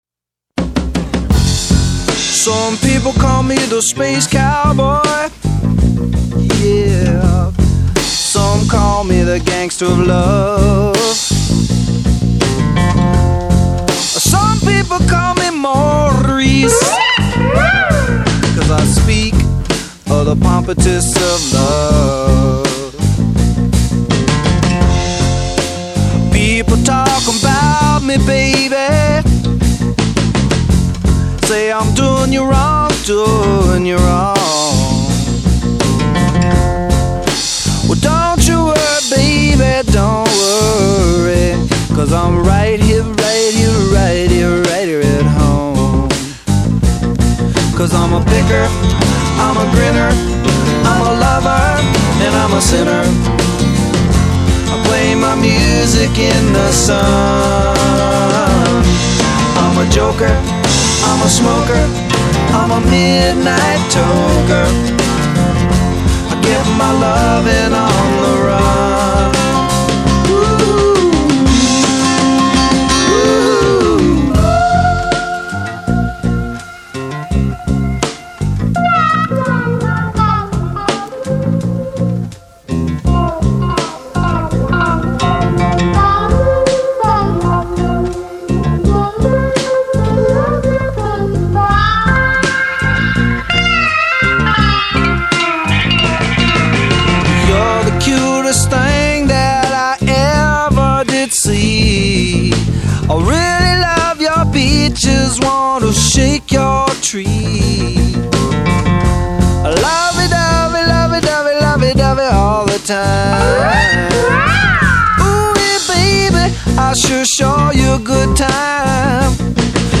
Classic Rock / Blues Rock